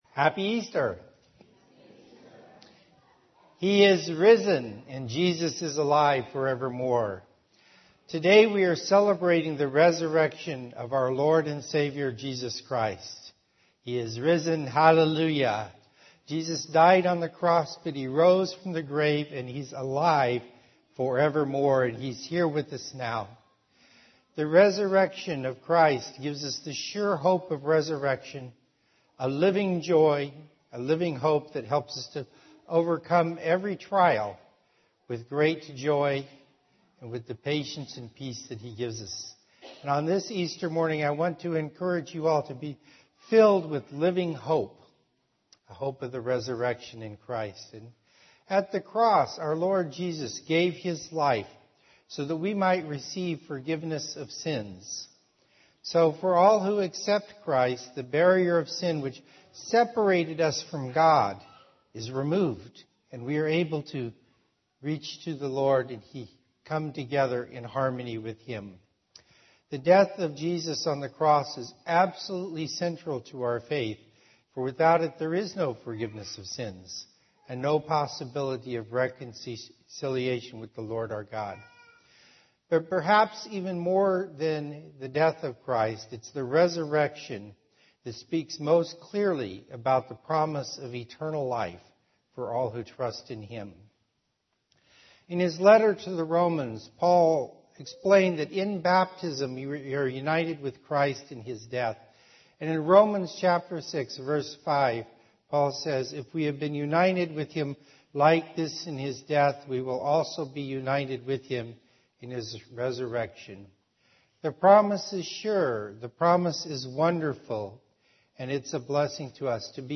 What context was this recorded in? Audio recordings and transcripts of Bible messages shared at OIC.